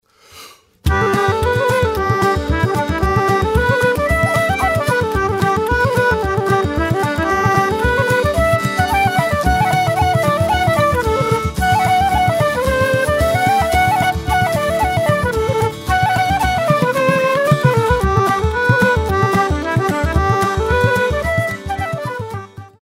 • 12 Bodhran Loops: Experience the heartbeat of Celtic music with authentic Bodhran drum loops, delivering powerful, rhythmic foundations that drive your tracks forward.
• 24 Celtic Music Loops: Dive into a variety of traditional Celtic melodies featuring fiddles, flutes, and pipes, designed to add an enchanting and mystical quality to your compositions.
• 12 Concertina Loops: Add a touch of folk charm with these intricate Concertina loops, providing a melodic and harmonic richness that enhances the Celtic atmosphere.
• 48 Folk Guitar Loops: From gentle, finger-picked patterns to lively, strummed rhythms, our folk guitar loops offer a versatile range of textures to complement your Celtic-inspired projects.